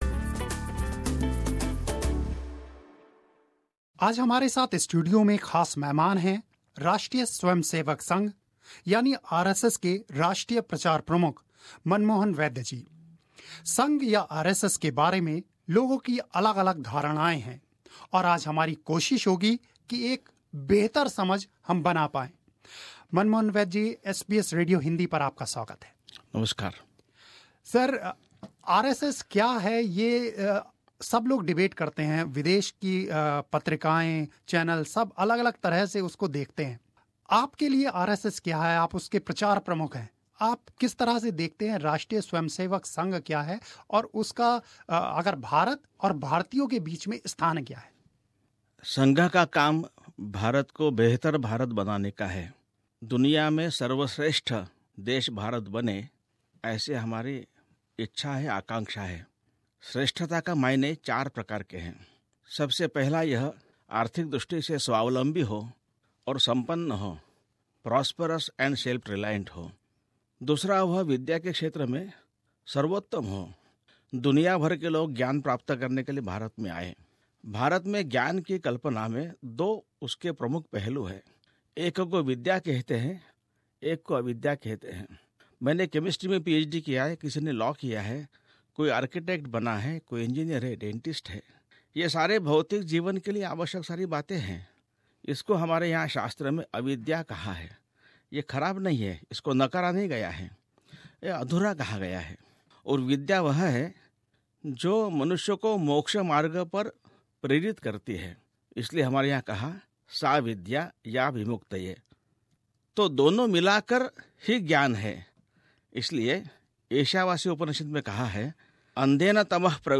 a candid interview